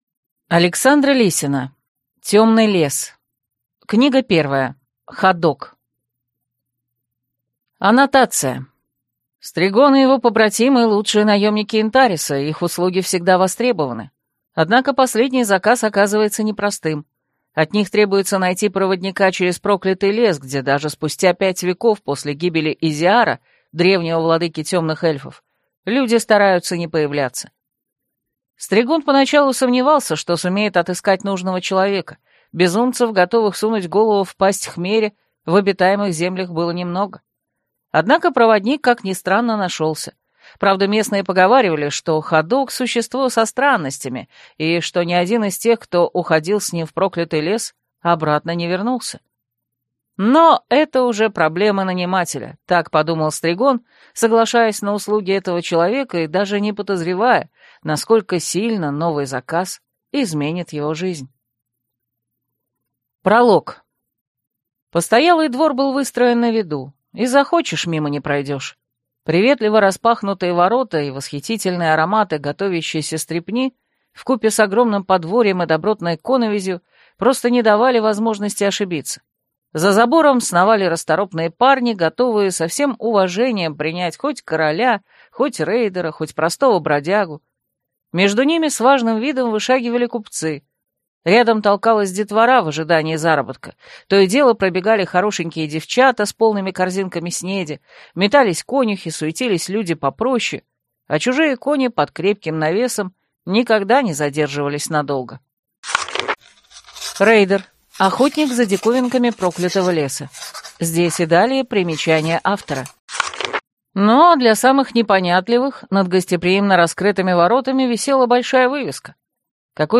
Аудиокнига Темный лес.